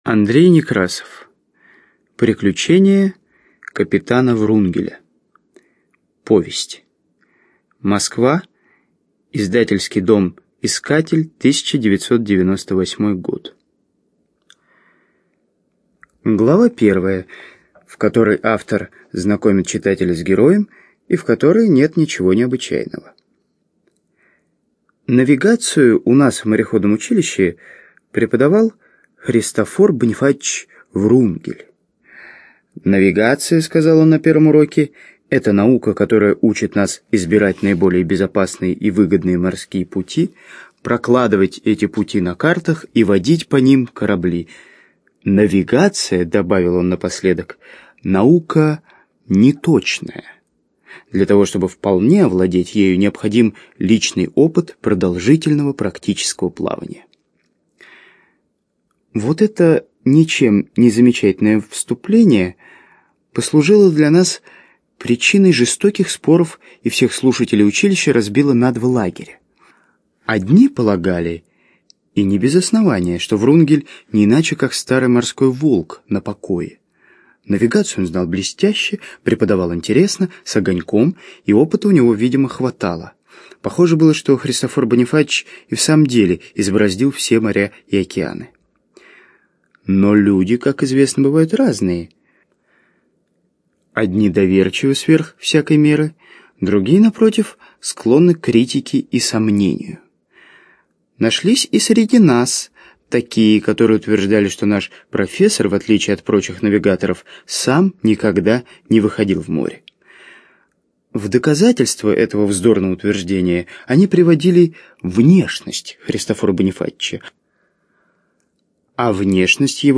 ЖанрПриключения, Детская литература, Сказки
Студия звукозаписиЛогосвос